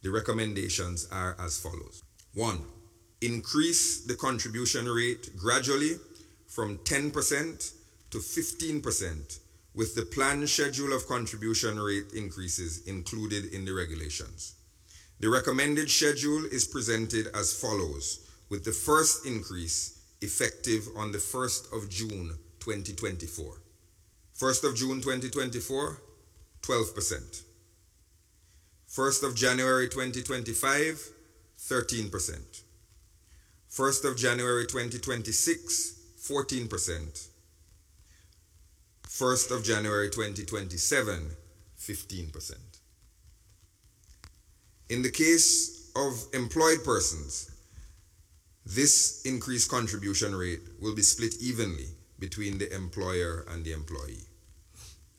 The Minister said in his Budget Address, that the changes are expected to improve financial sustainability of the NIS.